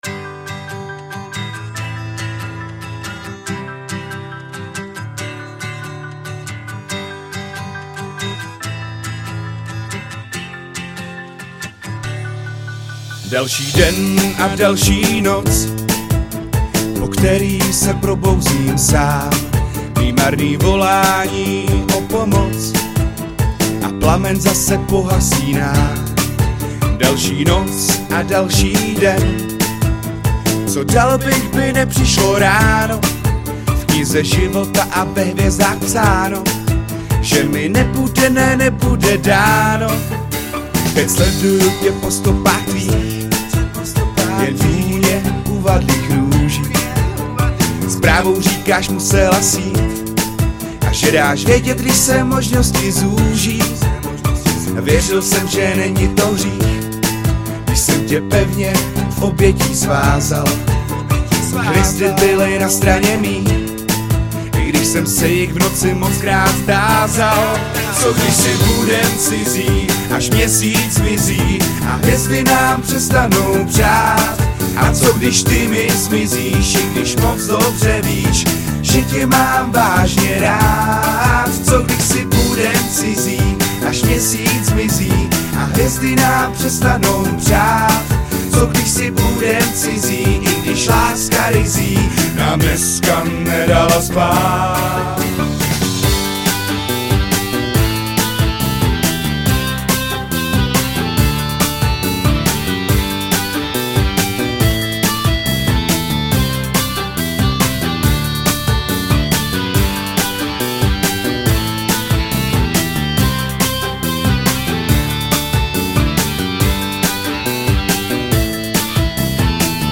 Žánr: Pop/Rock/Folk